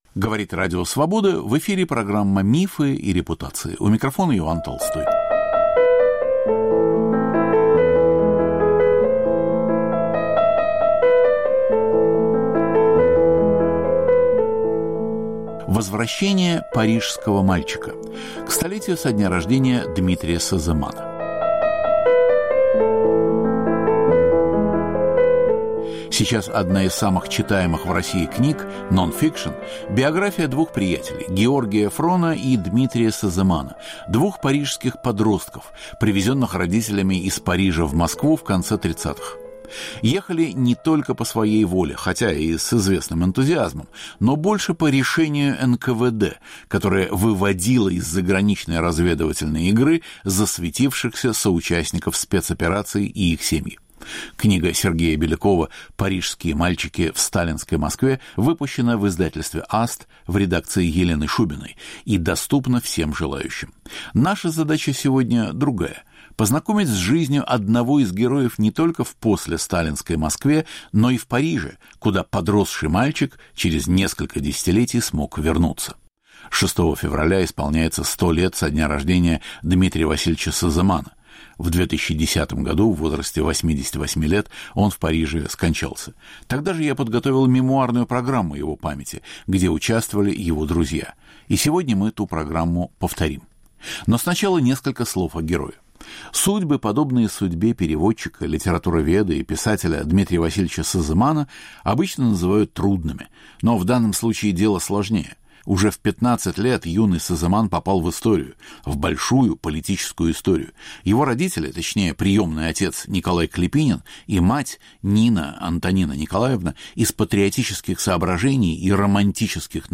В программе участвуют друзья Сеземана и сам Дмитрий Васильевич.